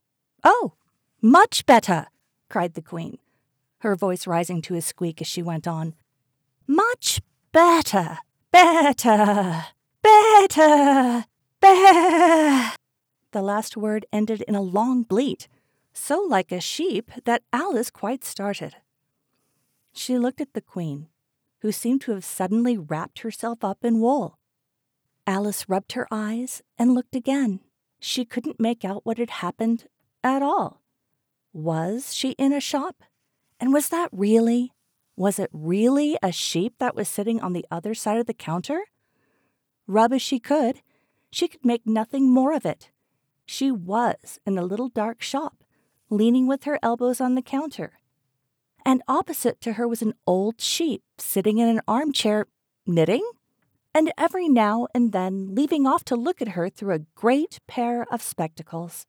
North American female neutral accent
Audio Book